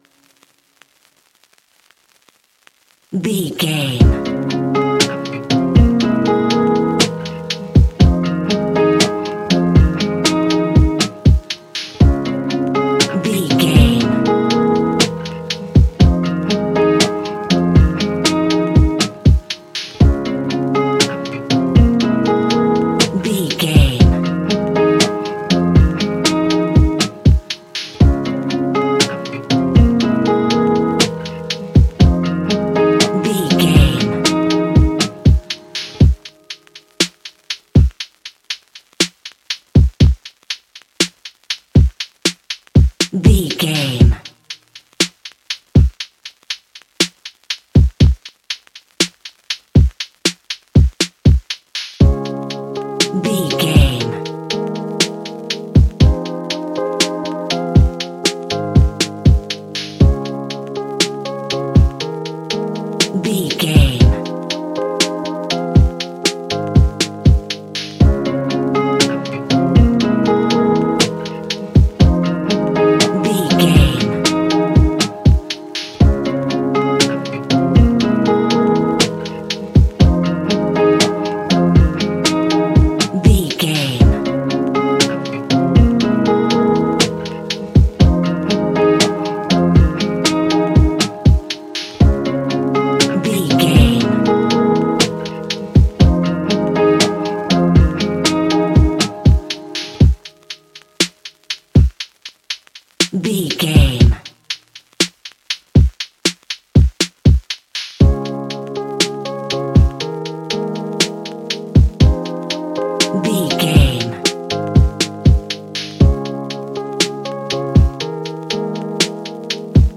Ionian/Major
G♯
chilled
laid back
Lounge
sparse
new age
chilled electronica
ambient
atmospheric
morphing
instrumentals